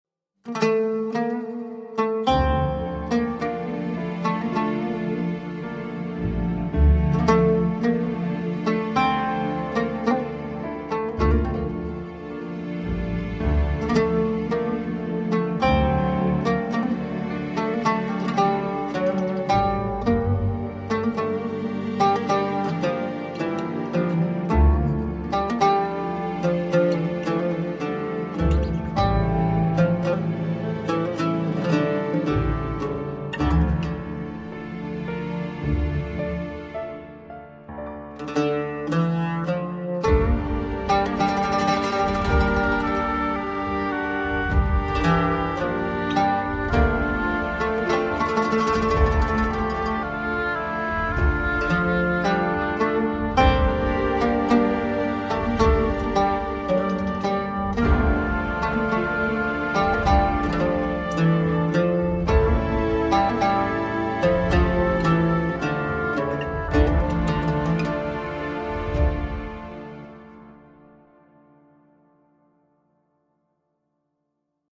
زیبا و حماسی خدا اجرتان دهد